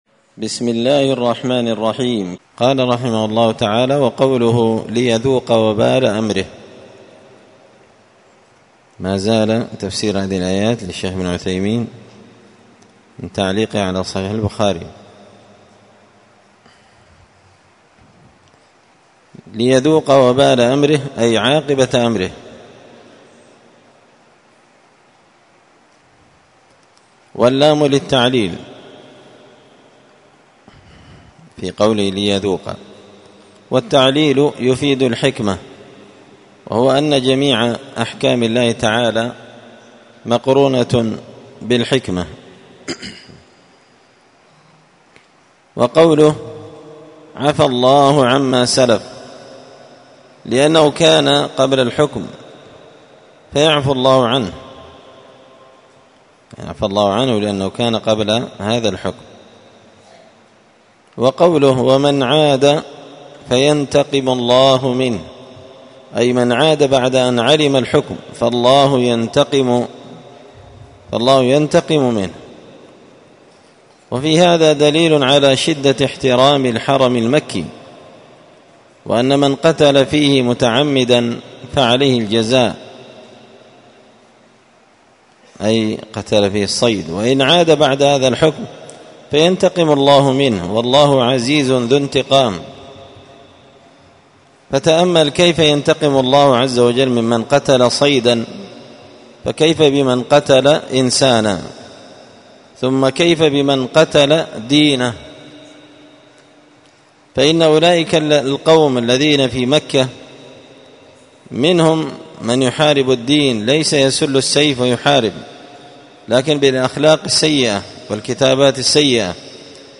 الثلاثاء 20 صفر 1445 هــــ | 5-كتاب جزاء الصيد، الدروس، شرح صحيح البخاري | شارك بتعليقك | 40 المشاهدات
مسجد الفرقان قشن المهرة اليمن